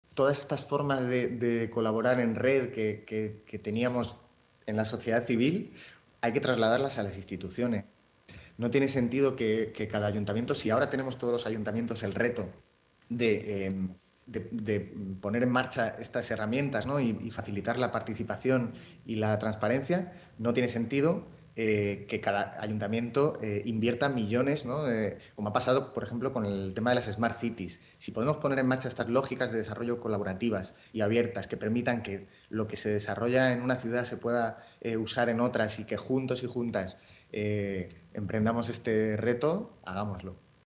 Nueva ventana:Declaraciones de Pablo Soto, concejal de Participación Ciudadana